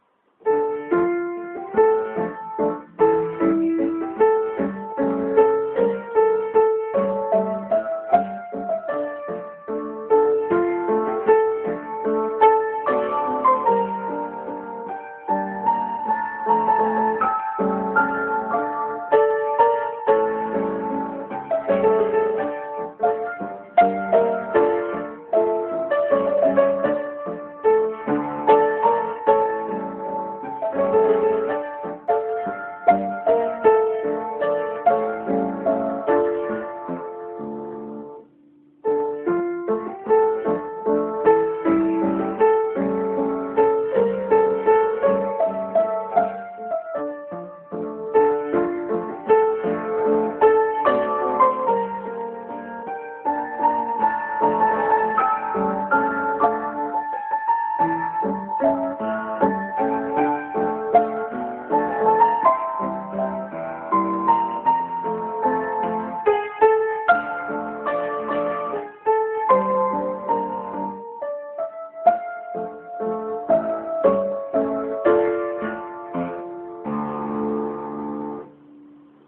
Вот как звучит вальс Л. Чернецкого «Tоска», воспроизведённый по симферопольскому нотному изданию (скачать):